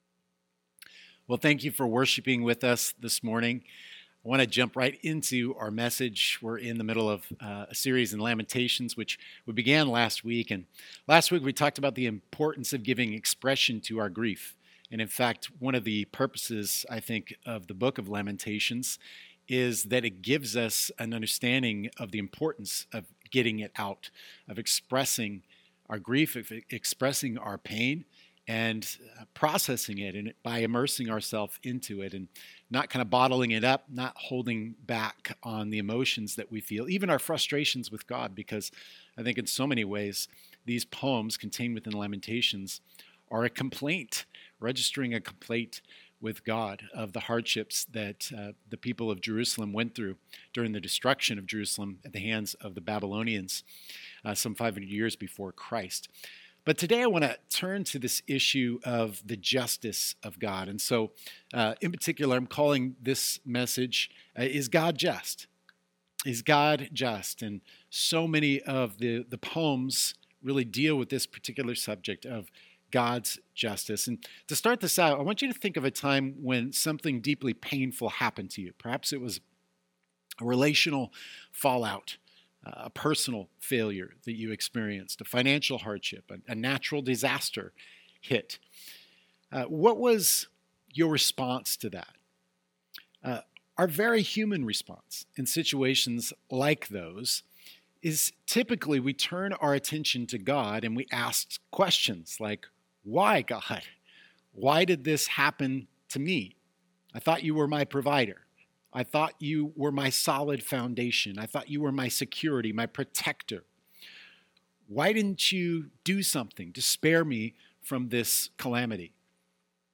Sermon-2.20.22.mp3